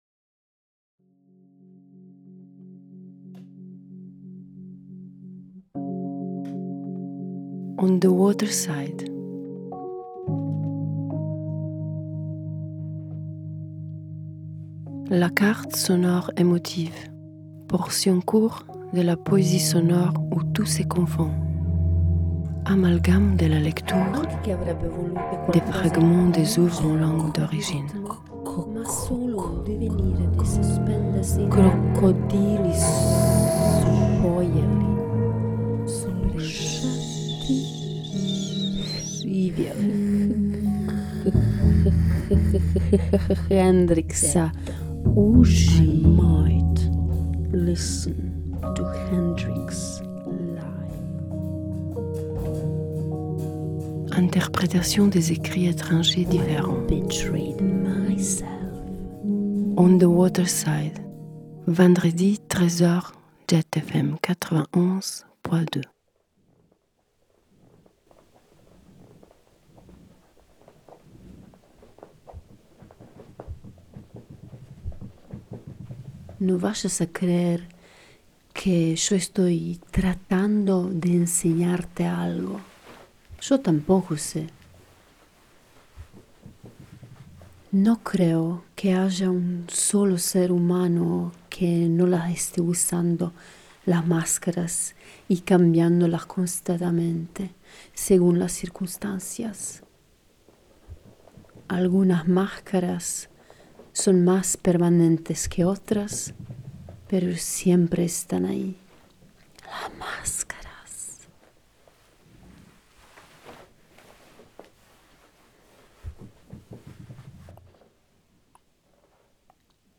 La portion court de la poésie sonore où tout se confonde.
Exploration de les sons dirty et les mélangeant avec des mélodies harmoniques. Chaque portion vous offre le son de la langue etranger superposés sur un tapis sonore crée exprès pour approcher l’ambiance. Cette fois au borde de l’eau , je choisis le court histoire de ecrivain guatemalteque Agosto Monterroso .
Cette fois ici sans les instruments - entierement c’est seulement ma voix avec y sans ces masques.